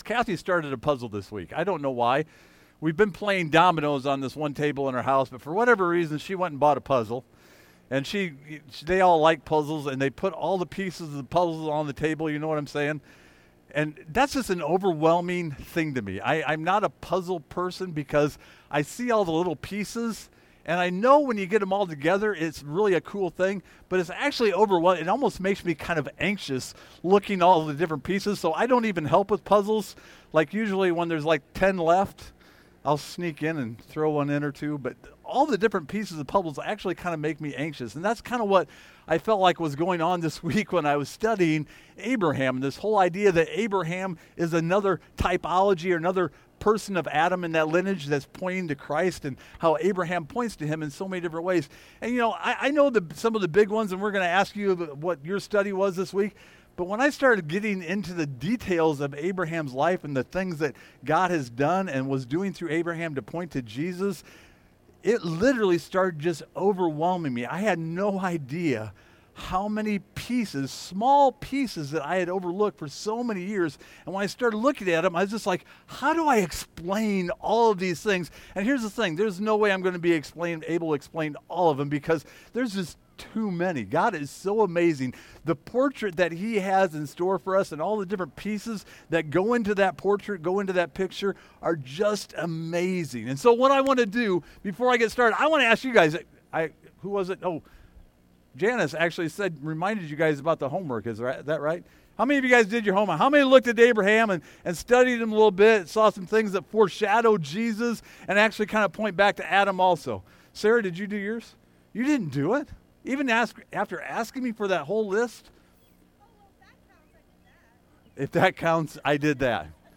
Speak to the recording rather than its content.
Service Type: Outdoor Service